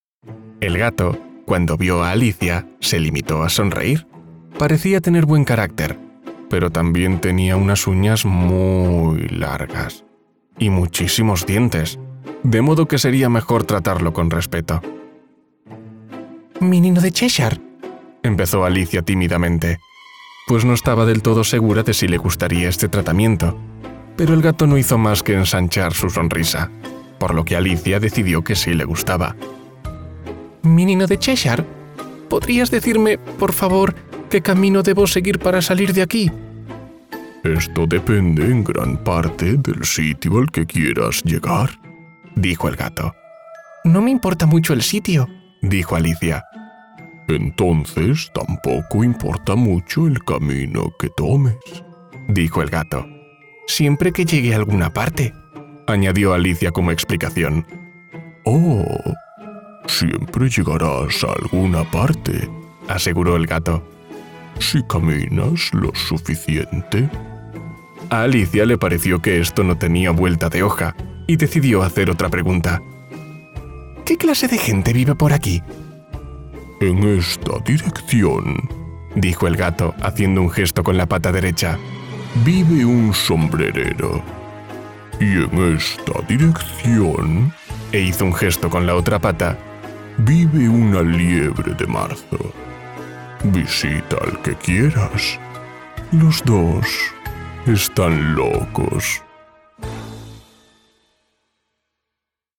Género: Masculino